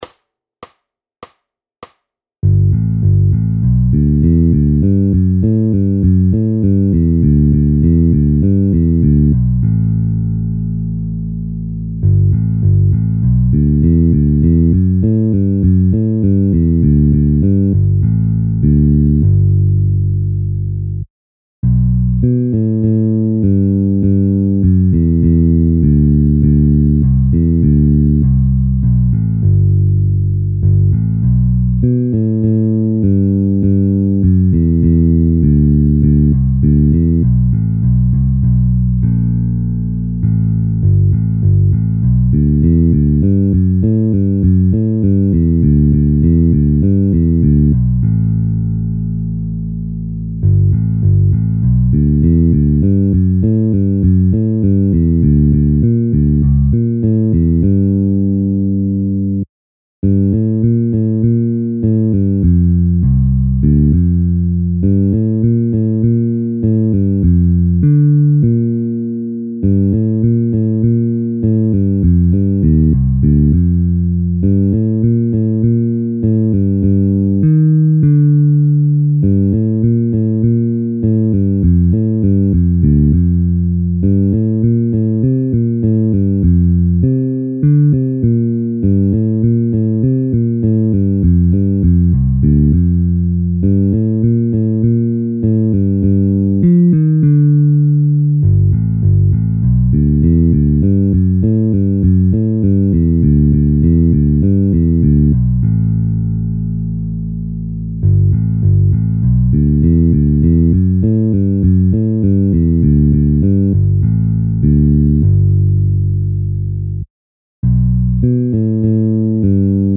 Hudební žánr Klasický